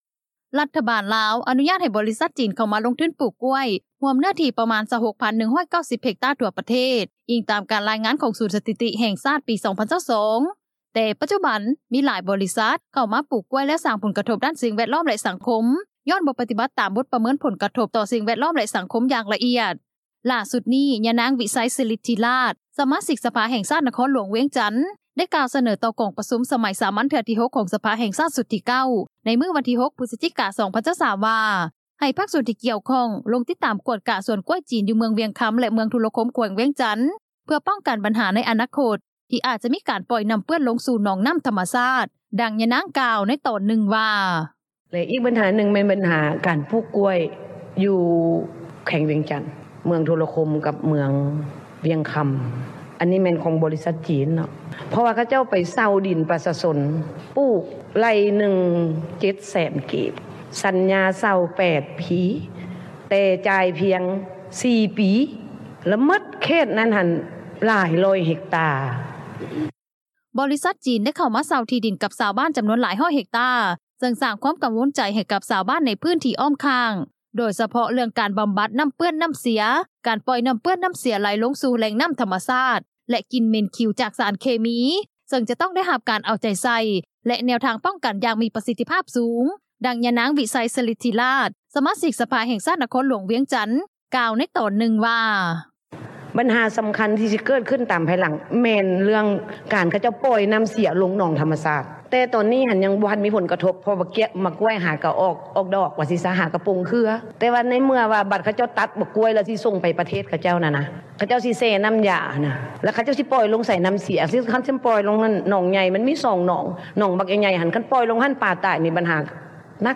ດັ່ງຍານາງ ວິໄຊ ສຣິດທິຣາດ ສະມາຊິກສະພາແຫ່ງຊາຕ ນະຄອນຫຼວງວຽງຈັນ ກ່າວໃນຕອນນຶ່ງວ່າ:
ດັ່ງຊາວບ້ານຜູ້ນີ້ ກ່າວຕໍ່ວິທຍຸເອເຊັຽເສຣີ ໃນມື້ວັນທີ 7 ພຶສຈິກາ ນີ້ວ່າ:
ດັ່ງເຈົ້າໜ້າທີ່ທ່ານນີ້ ກ່າວຕໍ່ວິທຍຸເອເຊັຽເສຣີ ໃນມື້ດຽວກັນນີ້ວ່າ: